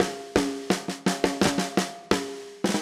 Index of /musicradar/80s-heat-samples/85bpm
AM_MiliSnareA_85-03.wav